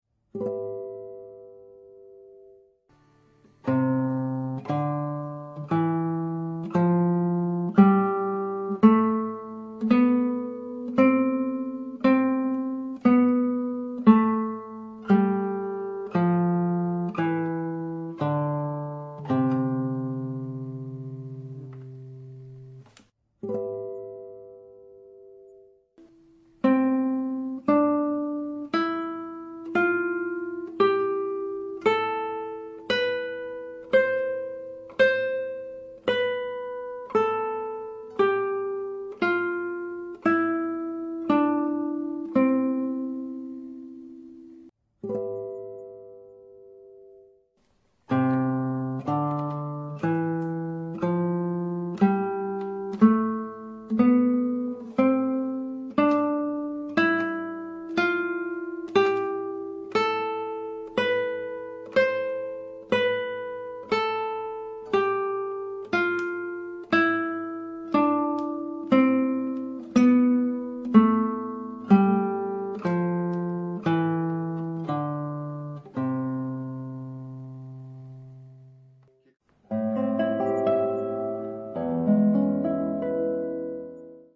Nr. 100 "Scales"
Elementar-3-100-Tonleiter.mp3